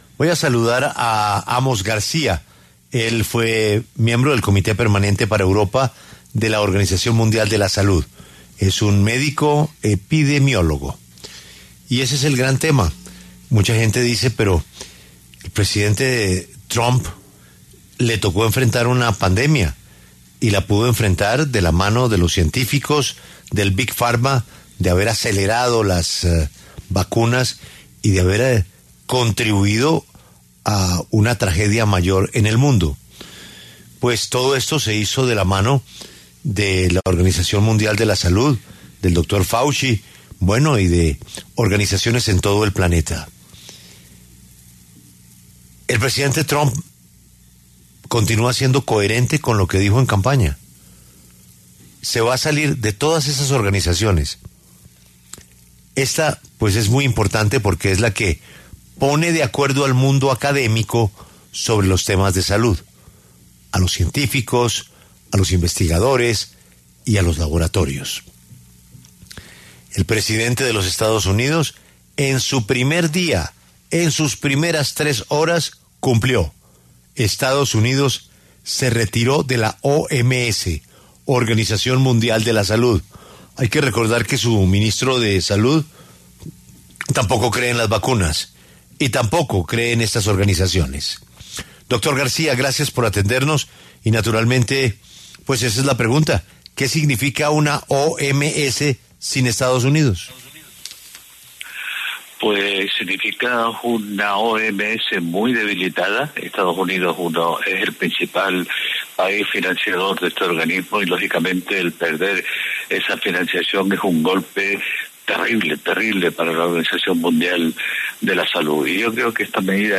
En conversación con La W